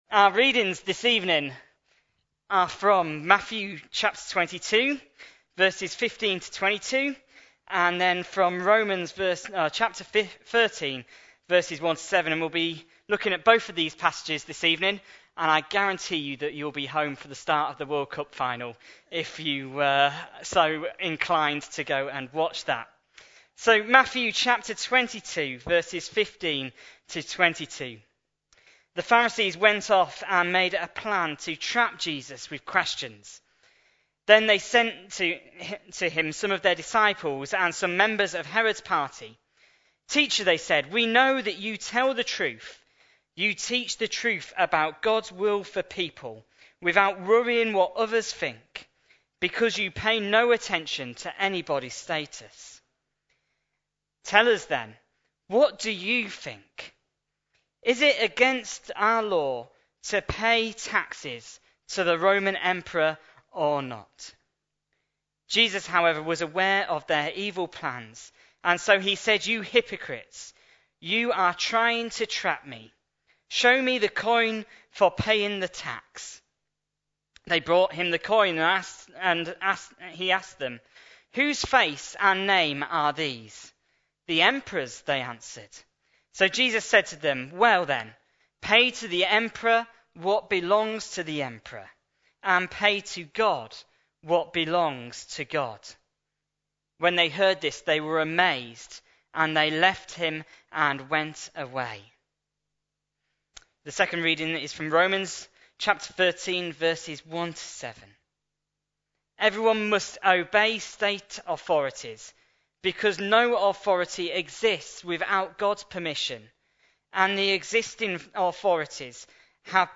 A sermon preached on 13th July, 2014, as part of our Connecting With Culture series.